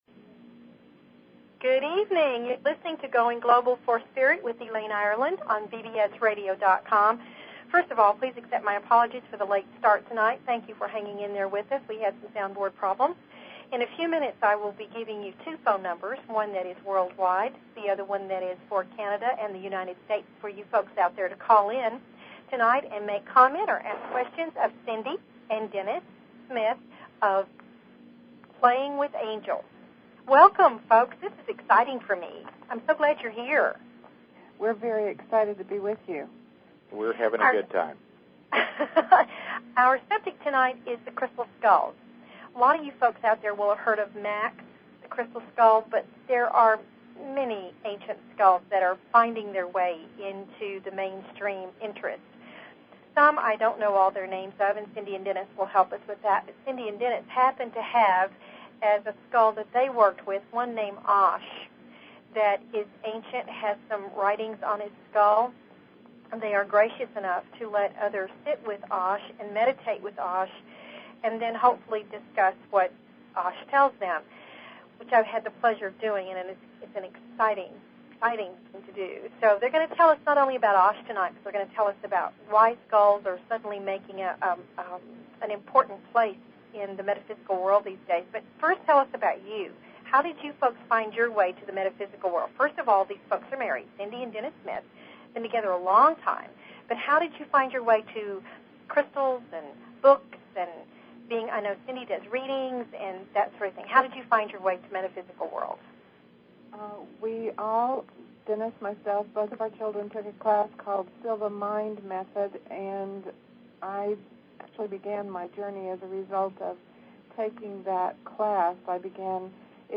Talk Show Episode, Audio Podcast, Going_Global_for_Spirit and Courtesy of BBS Radio on , show guests , about , categorized as
A variety of guests will be here to teach and share their wonders with you.